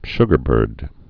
(shgər-bûrd)